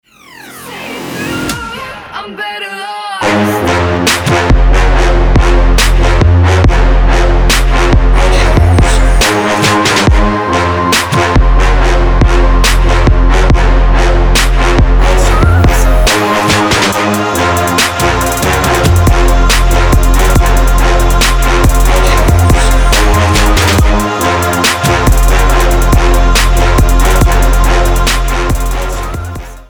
Trap Edit